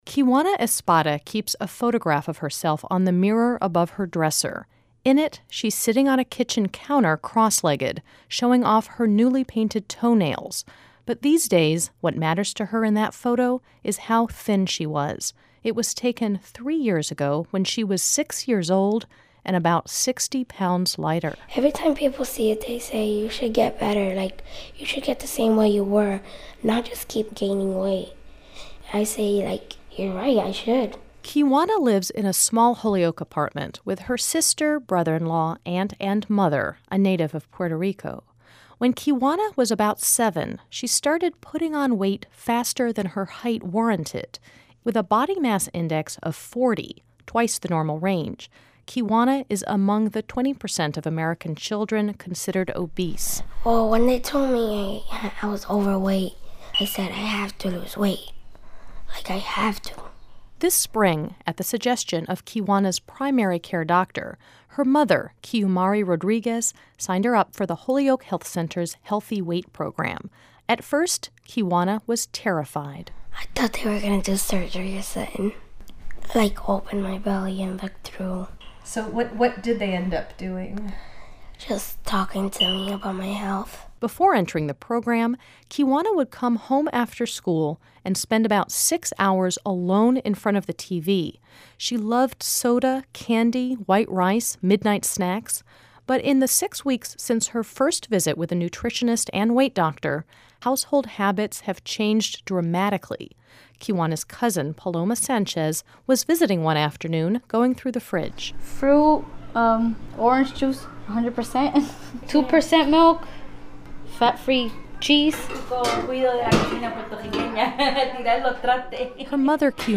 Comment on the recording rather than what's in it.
This series first aired on WFCR in July, 2010